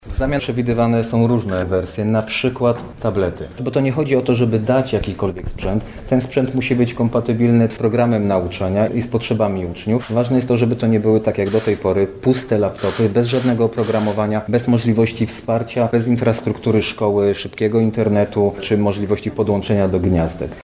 Poseł Marcin Józefaciuk mówił, dlaczego nie da się uruchomić systemu laptopów, które zapowiadał poprzedni rząd.